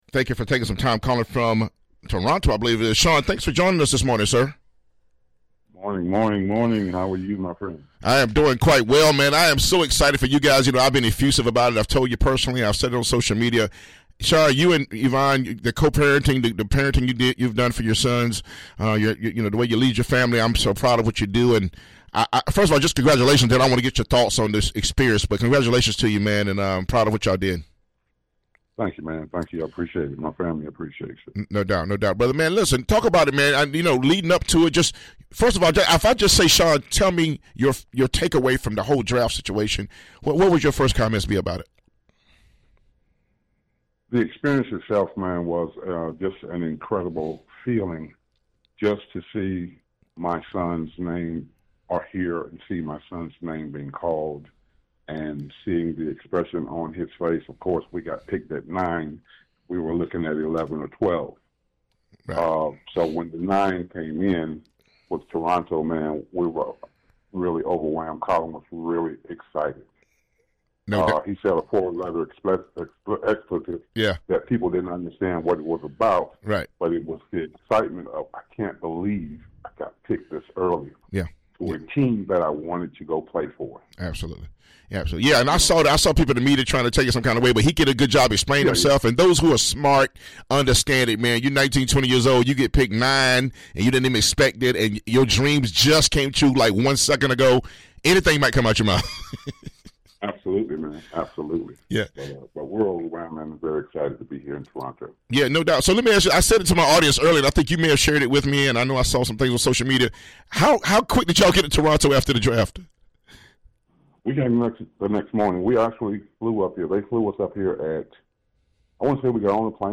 Miss the big interview?